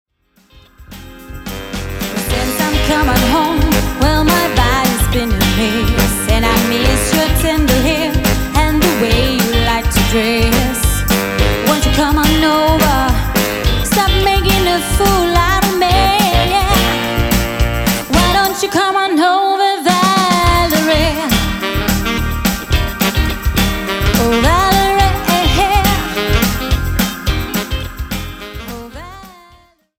live musik til din fest - pop & rock ørehængere fra 7 årtier
• Coverband